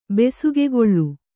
♪ besugegoḷḷu